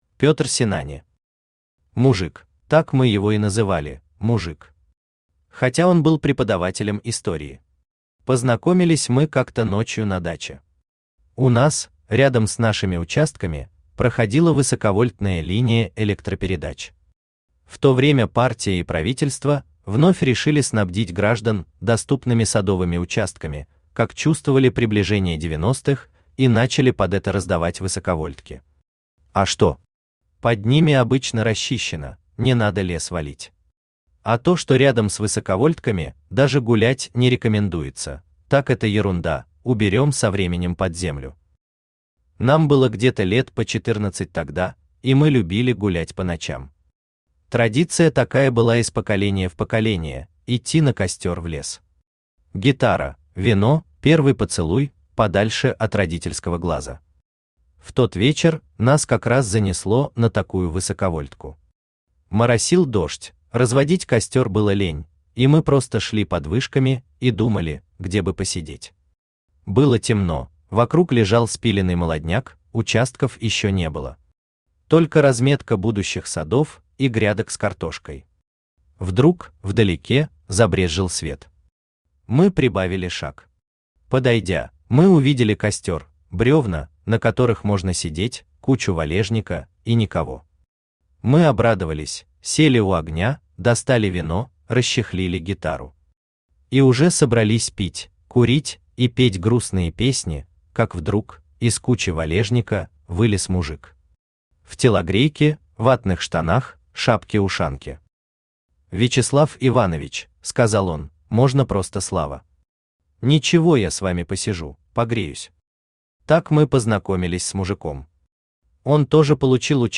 Аудиокнига Мужик | Библиотека аудиокниг